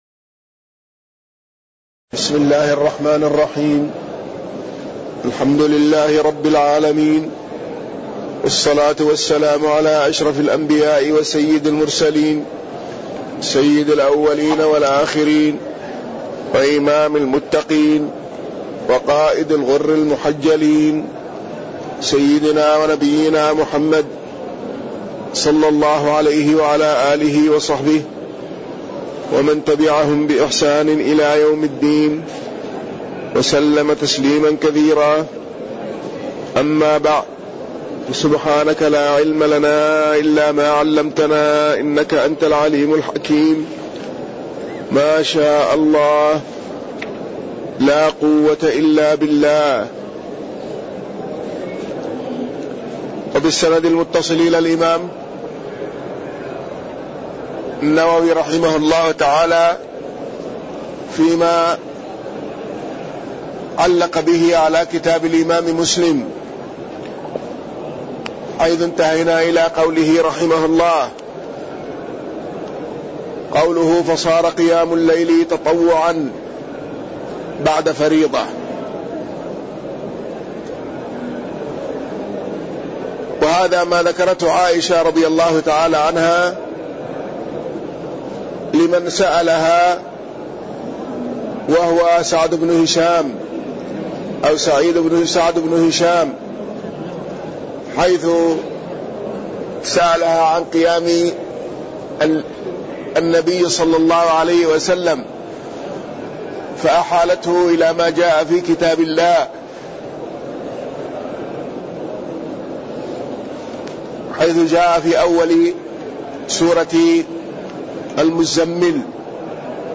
تاريخ النشر ١٦ ذو القعدة ١٤٣٠ هـ المكان: المسجد النبوي الشيخ